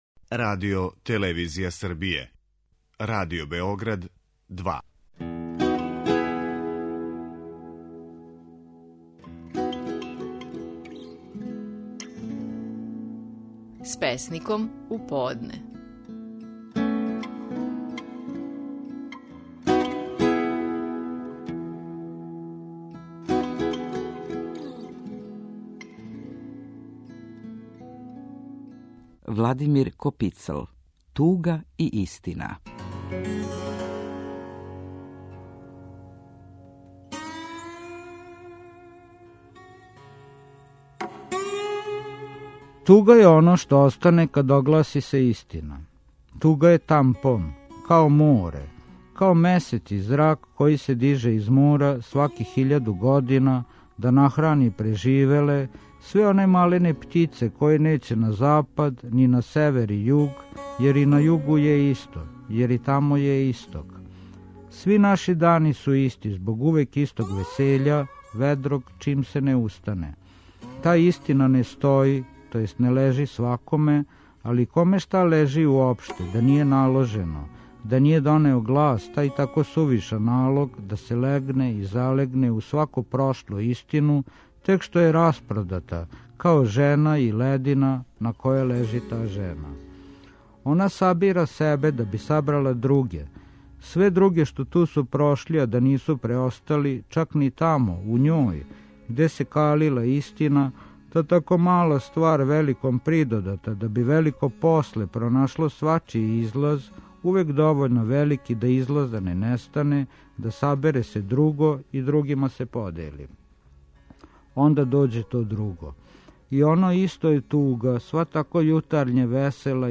Стихови наших најпознатијих песника, у интерпретацији аутора.
Владимир Копицл говори песму „Туга и истина".